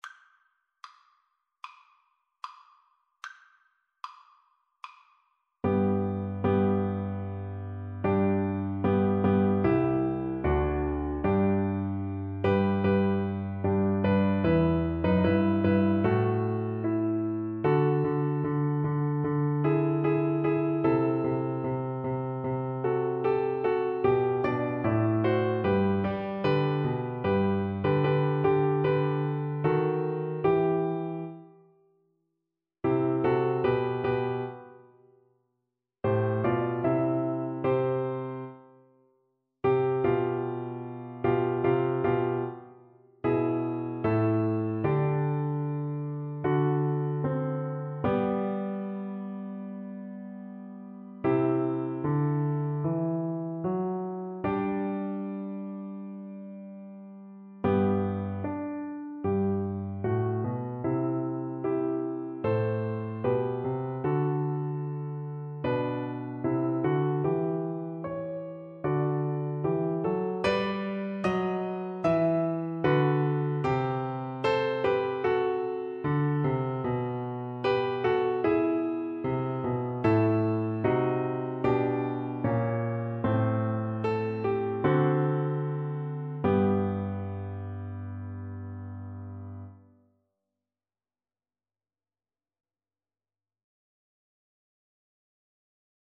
Classical (View more Classical Cello Music)